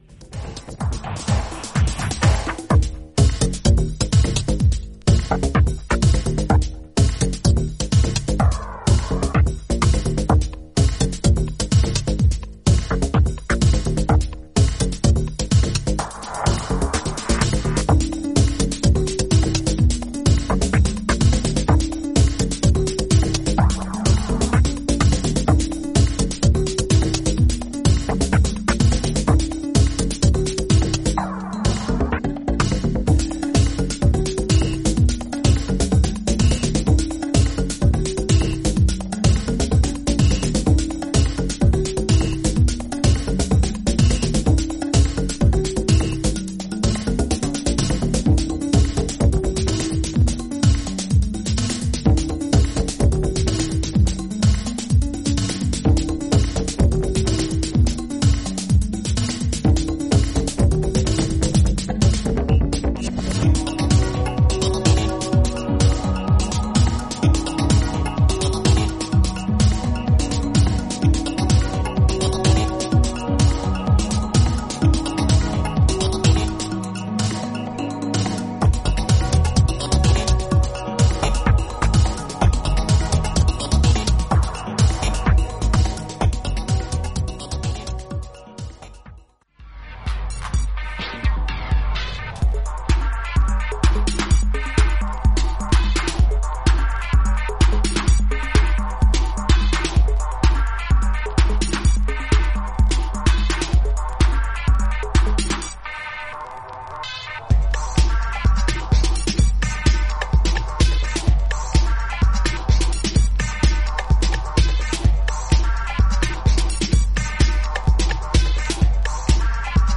当時のデトロイト・テクノを象徴するかの様なエレクトライブなビートとシンセ・ワークが印象的な